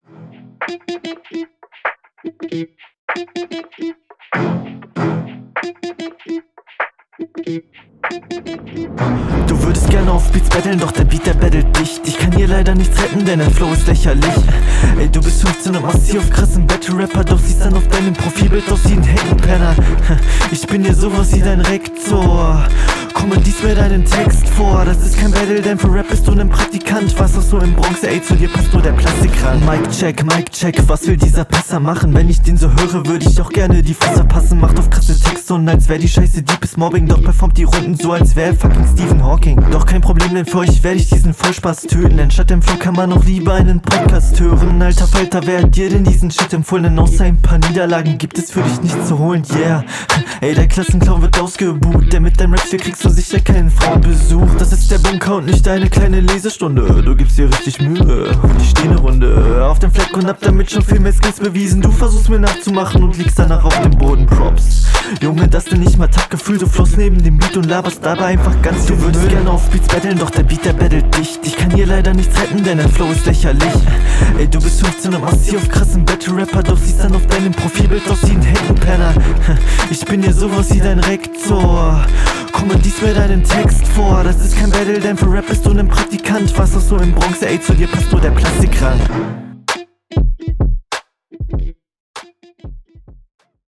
Paar nice Punches, Flow und Stimmeinsatz routiniert und auf gutem Level, Mische ist okay aber …